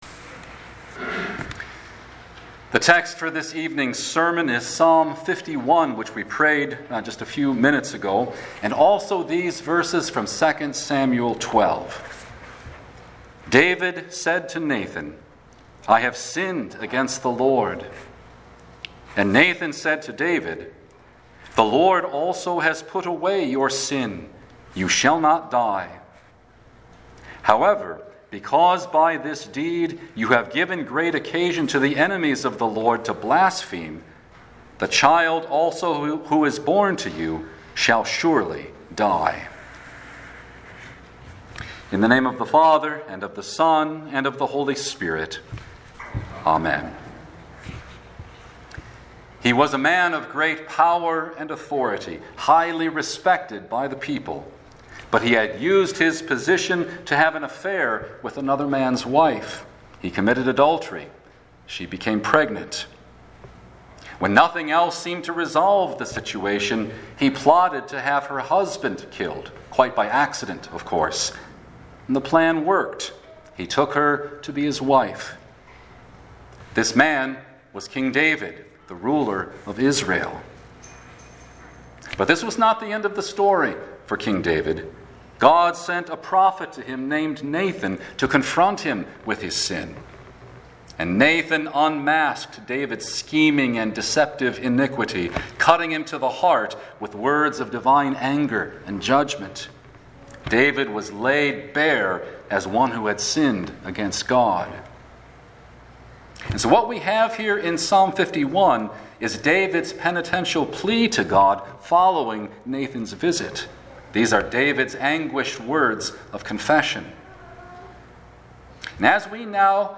Psalm 51; 2 Samuel 12:13-14 Ash Wednesday